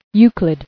[Eu·clid]